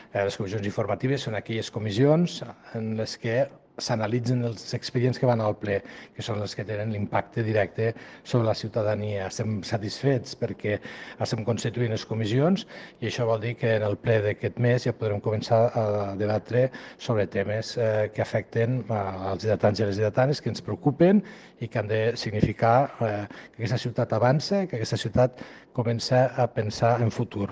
CORTE DE VOZ ALCALDE LARROSA